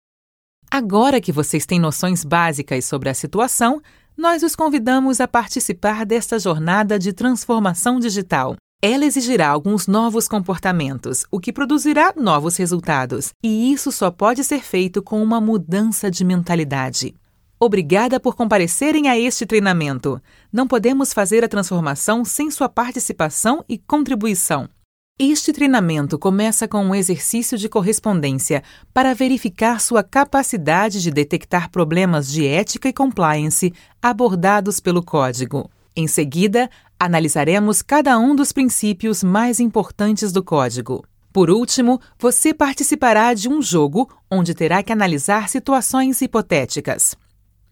Elearning Demo
Portuguese - Brazilian
Young Adult
Middle Aged